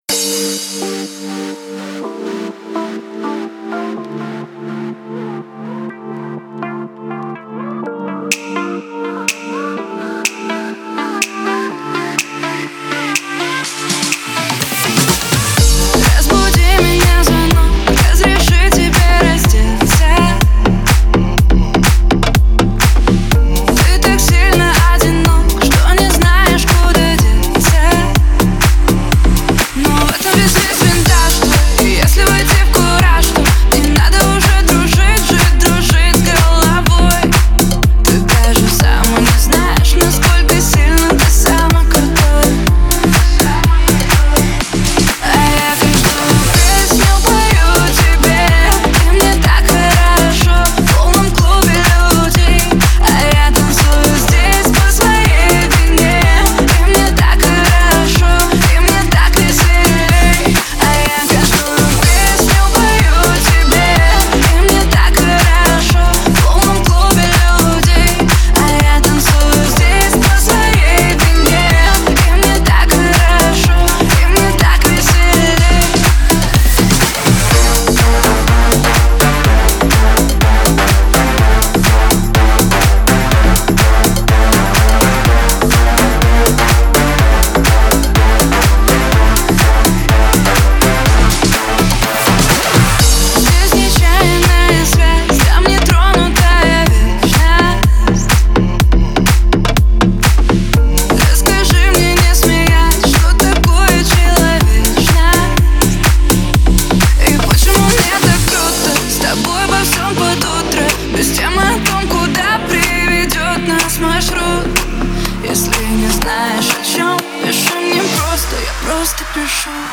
это яркий и энергичный трек в жанре поп и EDM